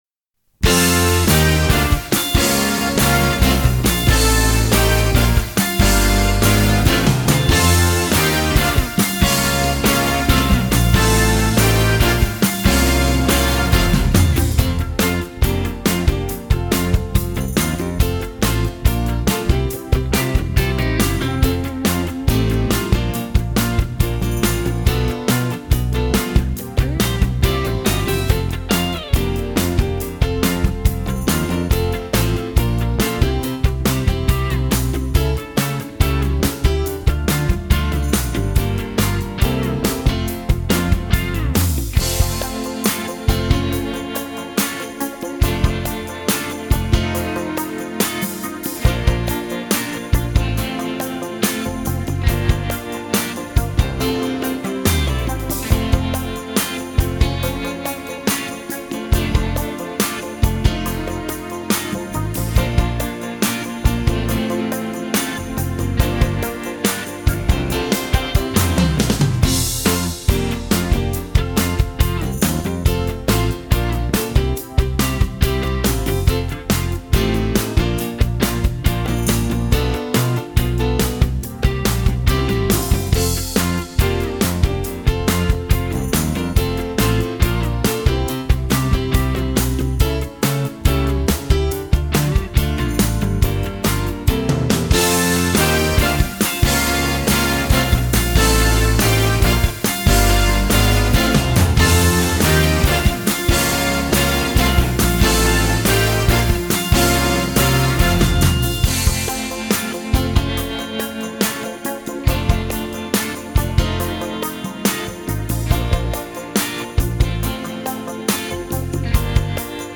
Wenn ihr den Coolen Träumer gut geübt habt, dann könnt ihr jetzt bestimmt mit mir im Wechsel singen ! Füllt die Lücken mit eurer Stimme und versucht auch mal zu dem Playback zu singen.